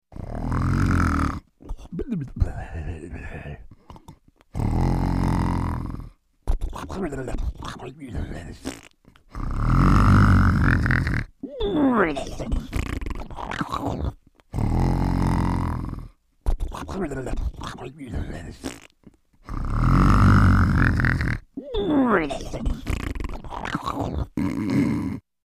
Звуки мультяшного храпа
Интересный и комичный храп